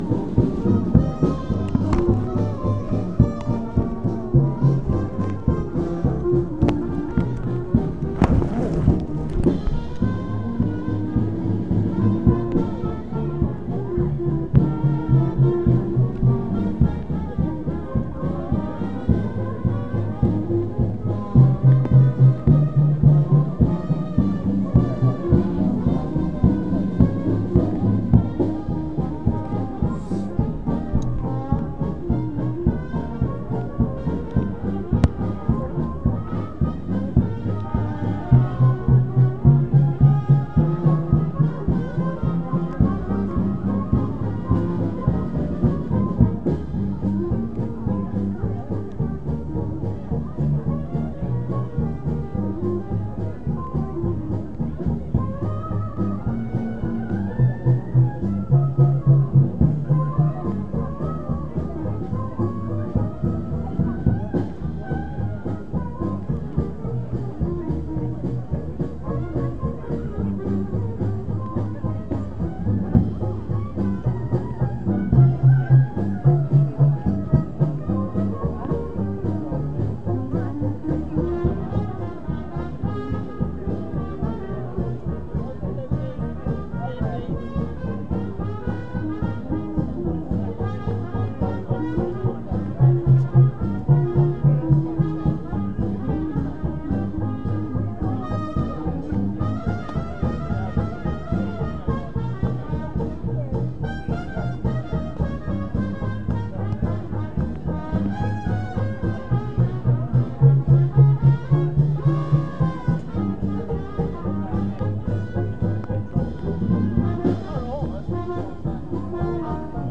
Sauce City Jazz 3, with fireworks at end
From Monday night's Worcester Festival 2012 Finale